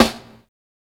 Snares
SNARE_BAD_MUTHA_2.wav